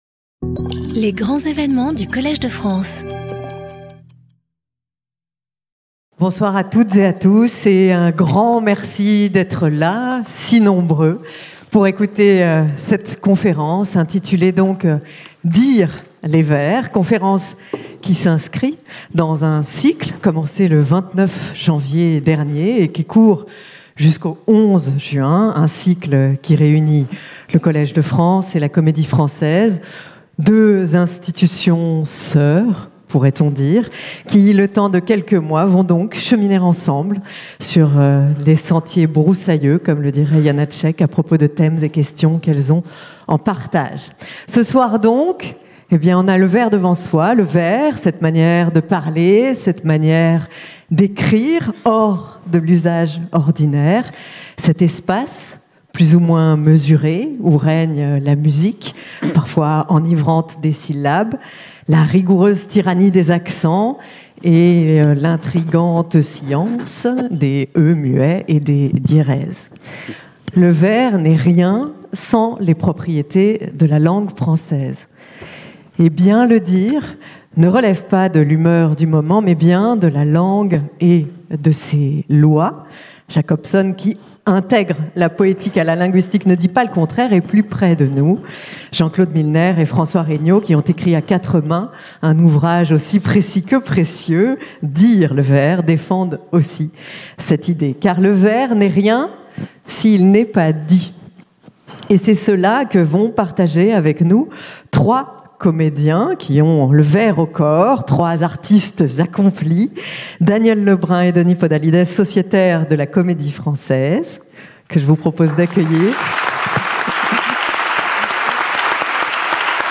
Trois grands diseurs, Éric Ruf, Denis Podalydès et Danièle Lebrun, évoquent la modernité du vers de Racine à Claudel.